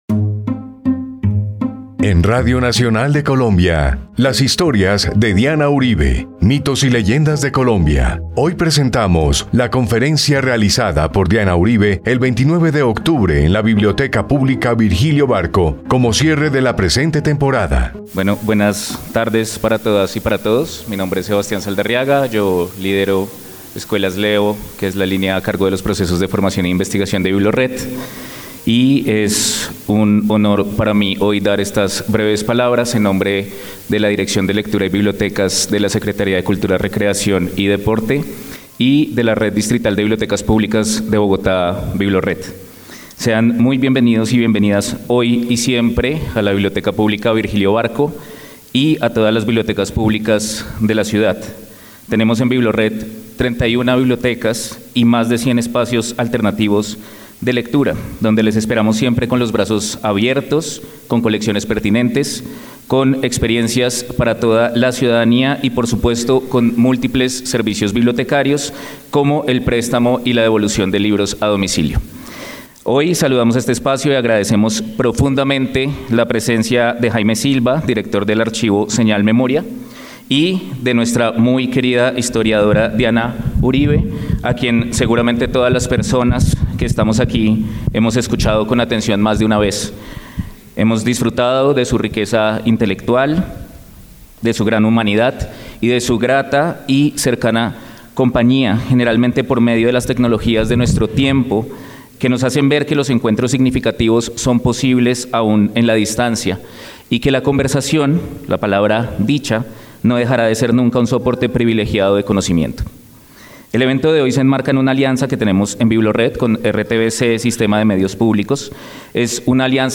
Una conversación, desde la Biblioteca Pública Virgilio Barco, con la anfitriona de Las historias de Diana Uribe sobre el universo mágico que rodea los mitos y leyendas de Colombia, y su experiencia personal al reencontrarse con estos relatos.